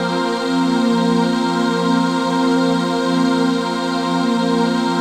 DM PAD2-58.wav